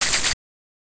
PixelPerfectionCE/assets/minecraft/sounds/mob/silverfish/kill.ogg at mc116